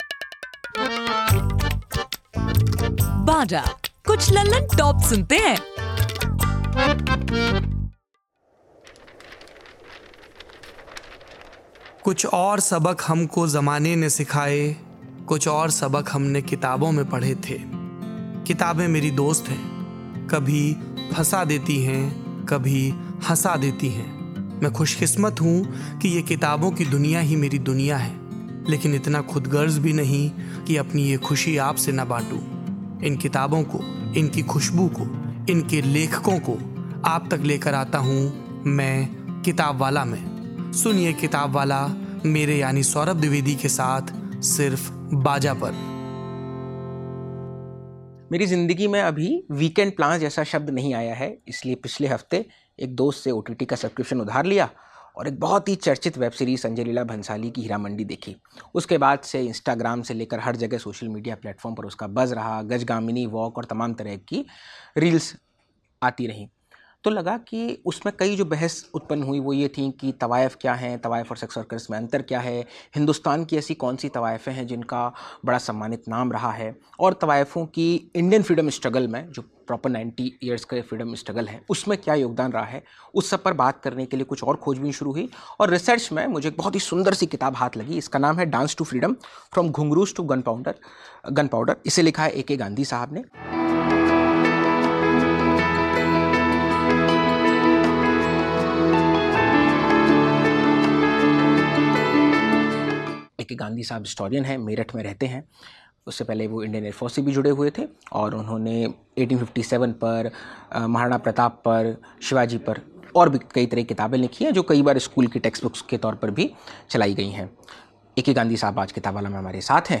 बातचीत.